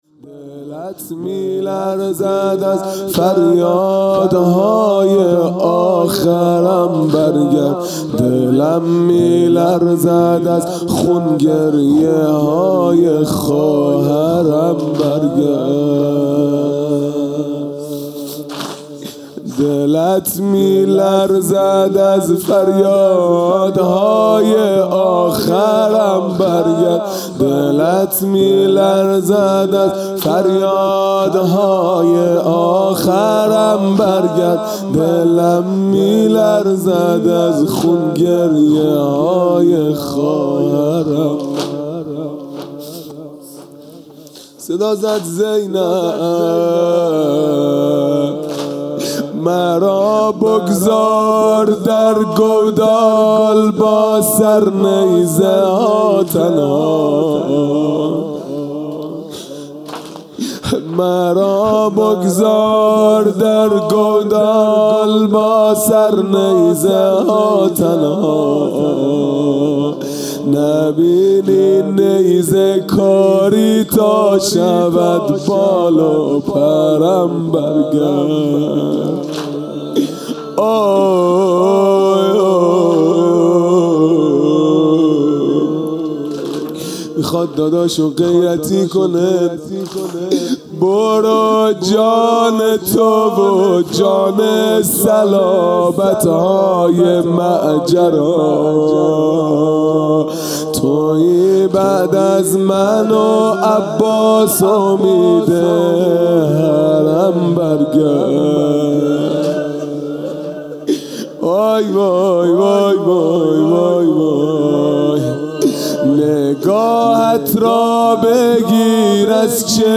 خیمه گاه - هیئت بچه های فاطمه (س) - واحد | دلت میلرزد از فریادهای آخرم